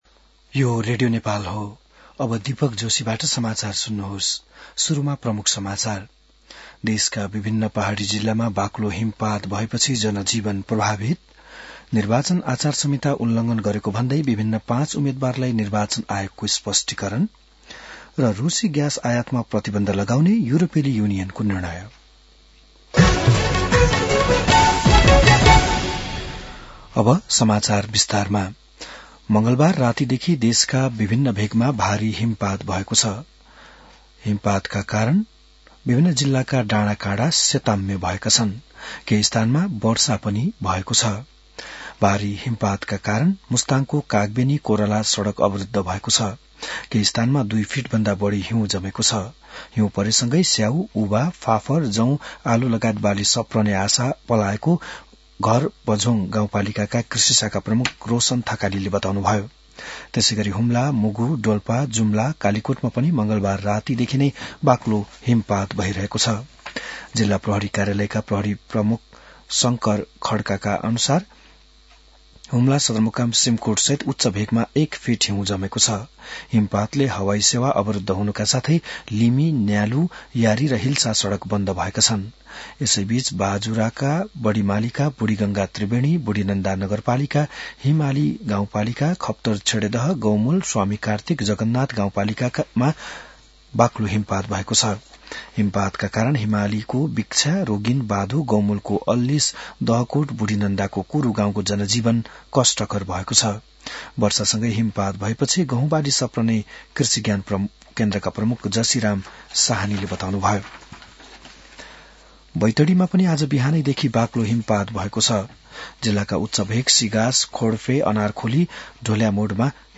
बिहान ९ बजेको नेपाली समाचार : १४ माघ , २०८२